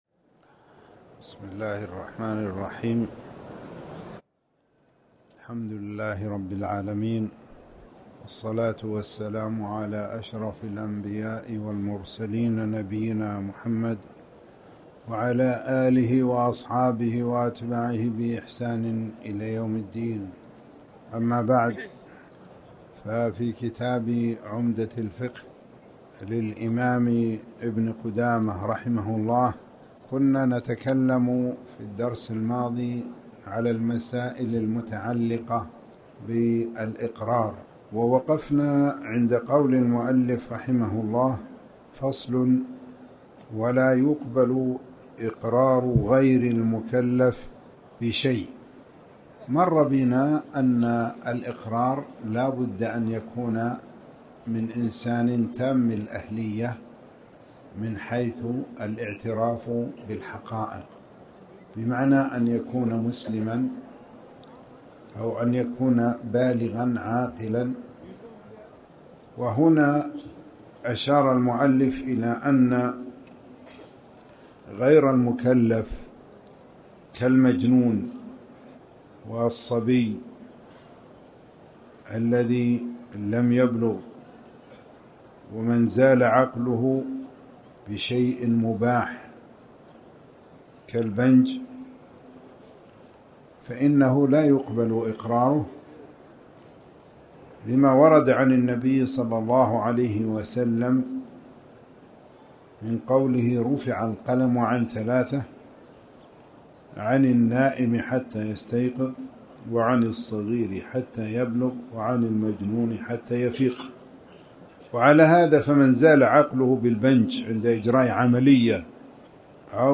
تاريخ النشر ٢٣ ربيع الثاني ١٤٣٩ هـ المكان: المسجد الحرام الشيخ